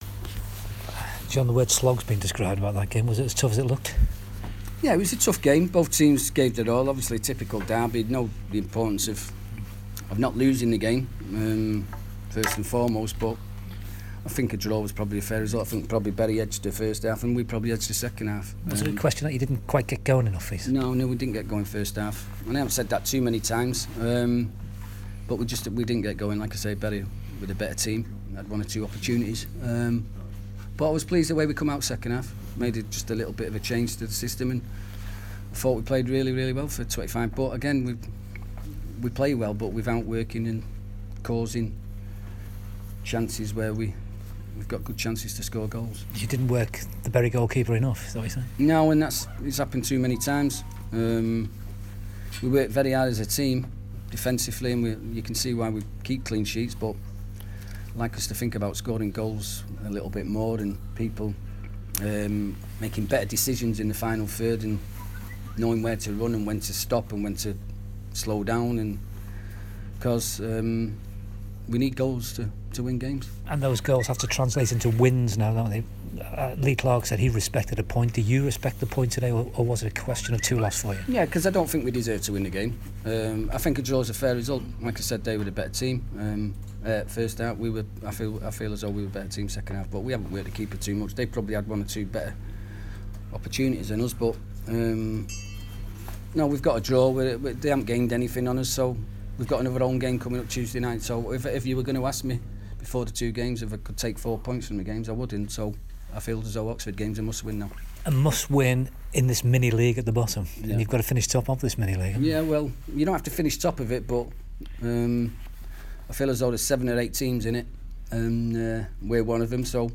John Sheridan, manager of Oldham Athletic has his say on the stalemate at home to local rivals Bury.